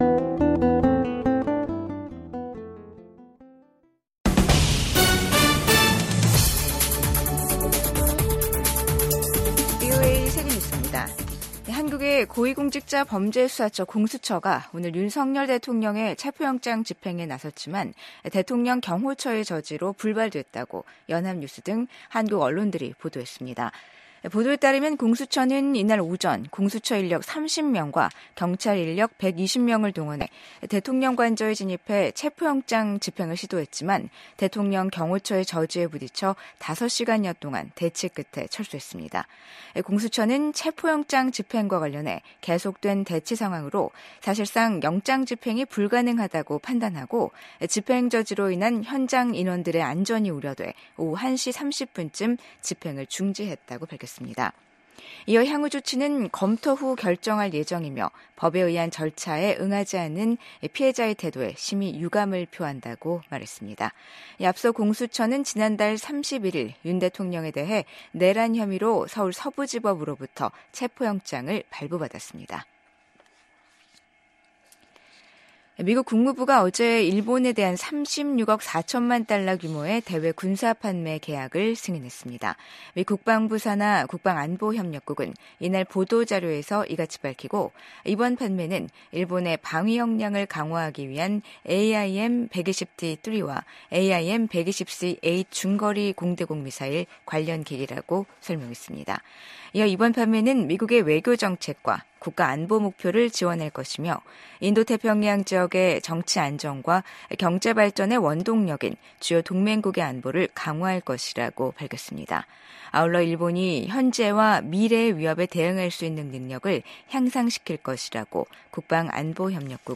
VOA 한국어 간판 뉴스 프로그램 '뉴스 투데이', 2025년 1월 3일 2부 방송입니다. 한국에서 현직 대통령에 대한 사법당국의 체포 시도라는 사상 초유의 일이 벌어졌습니다. 최상목 한국 대통령 권한대행 부총리 겸 기획재정부 장관은 오늘(3일) 오후 정부서울청사에서 필립 골드버그 주한 미국대사와 제이비어 브런슨 주한미군사령관을 공동 접견했습니다. 2025년 새해가 밝았지만, 북한군 병사들이 타국의 전쟁터에서 희생되고 있다는 소식이 이어지고 있습니다.